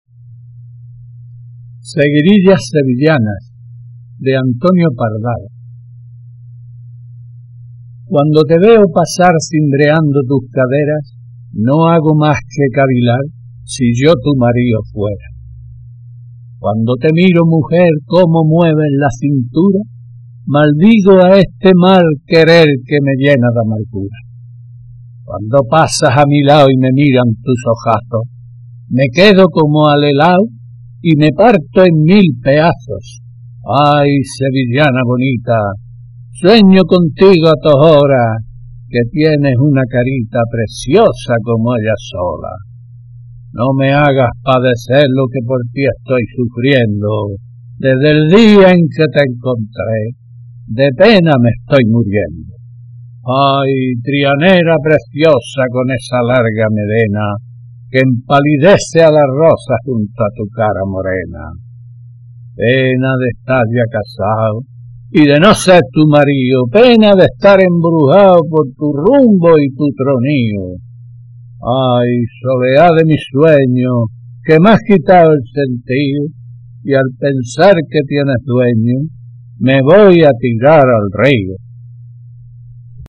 Y digo nos lo está haciendo, porque después de esas dos primeras declamaciones me ha mandado otras tres más, muy buenas también.